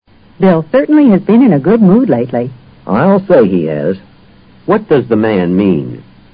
托福听力小对话【96】in a good mood